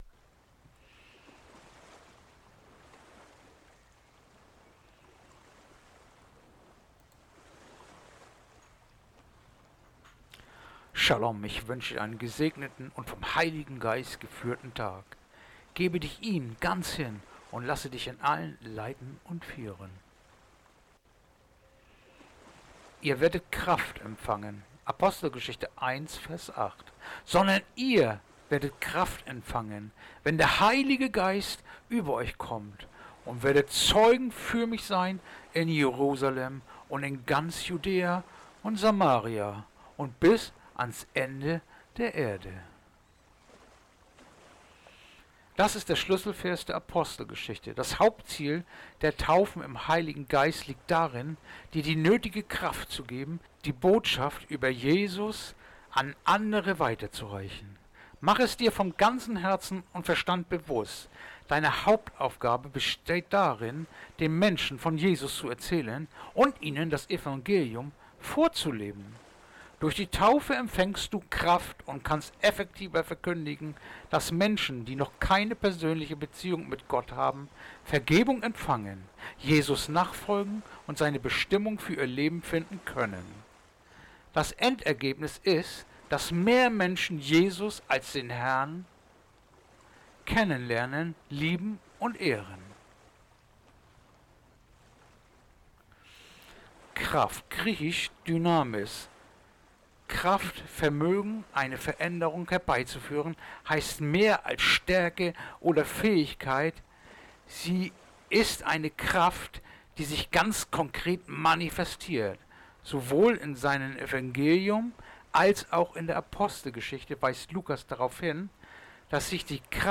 Andacht-vom-10-Juli-Apostelgeschichte-1-8